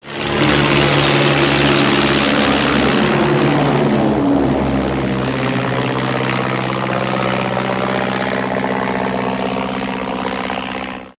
Avioneta en vuelo se aleja